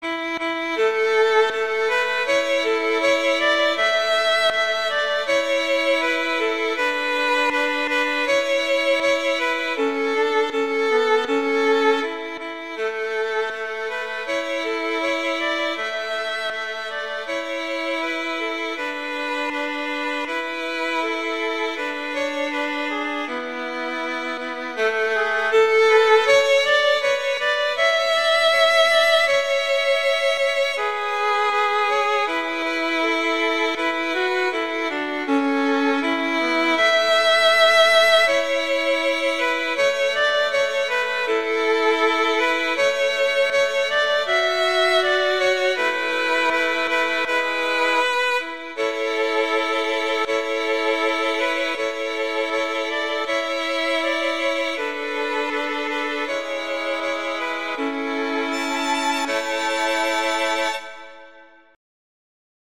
A major
♩=80 BPM
G#3-A5